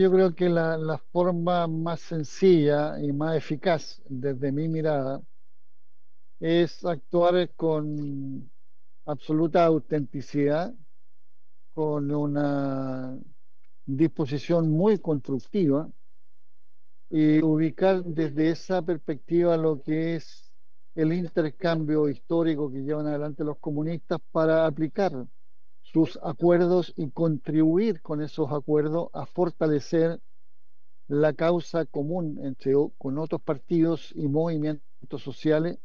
Desde la romería que se realizó el domingo en el Cementerio General en homenaje del ex presidente del Partido Comunista, Guillermo Teillier, el actual timonel de la colectividad, Lautaro Carmona, compartió palabras de reconocimiento.